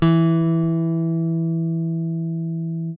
ALEM PICK E3.wav